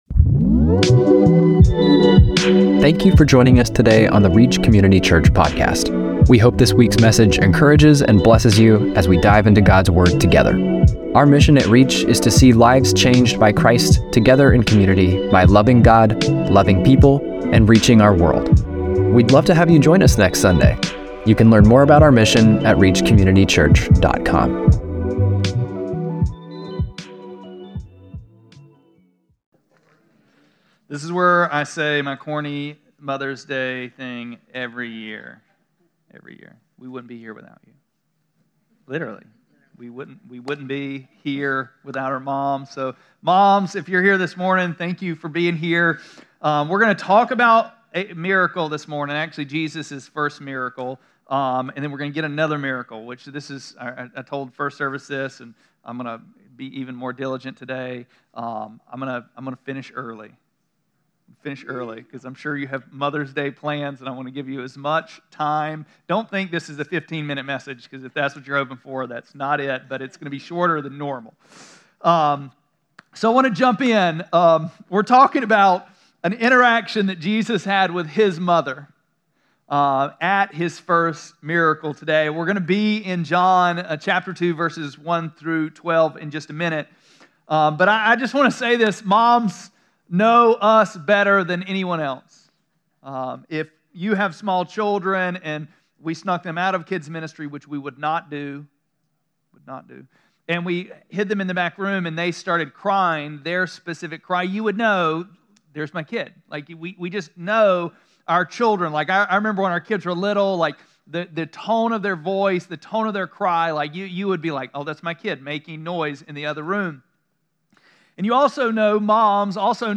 5-12-24-Sermon.mp3